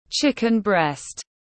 Thịt ức gà tiếng anh gọi là chicken breast, phiên âm tiếng anh đọc là /ˈʧɪkɪn brɛst/
Chicken breast /ˈʧɪkɪn brɛst/